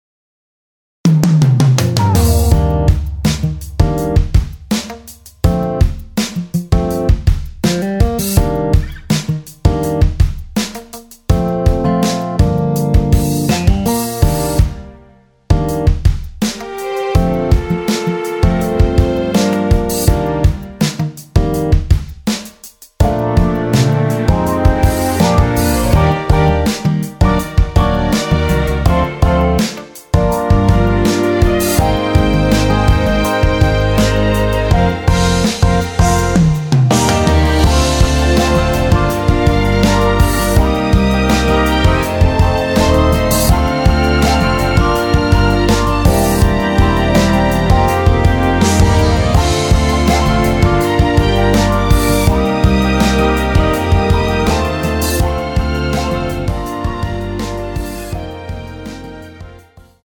Bb
◈ 곡명 옆 (-1)은 반음 내림, (+1)은 반음 올림 입니다.
앞부분30초, 뒷부분30초씩 편집해서 올려 드리고 있습니다.
중간에 음이 끈어지고 다시 나오는 이유는